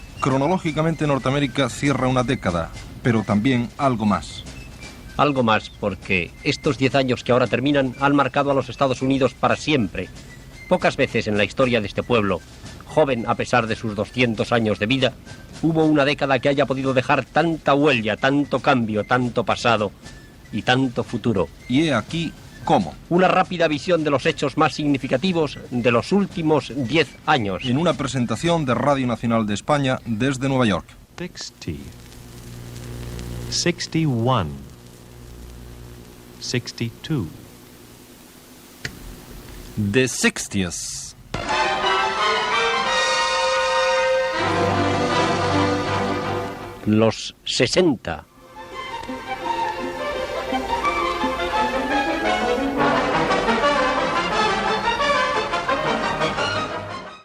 Presentació del programa dedicat a la dècada de 1960 als Estats Units i careta
Informatiu